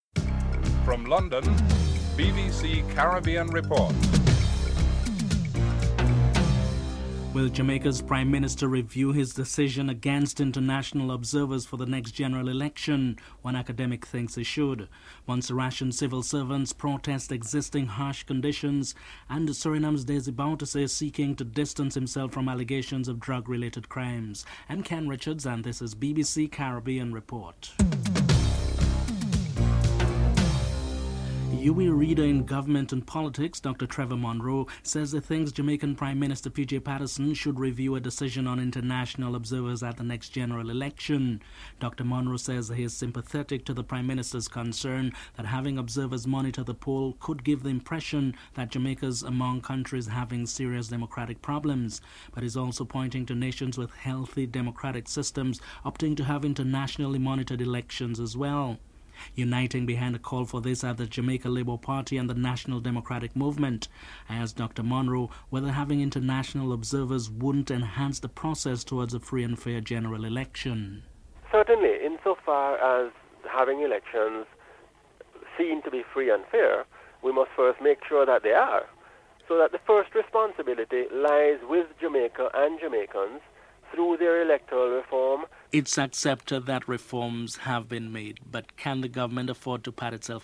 1. Headlines (00:00-00:30)
3. Guyana's Opposition Leader Desmond Hoyte says he hopes that the country's Election Commission will be able to deliver on an election promise to have the voters list ready by the end of August and that general elections later this year would run smoothly. Opposition Leader, Desmond Hoyte is interviewed (04:13-05:13)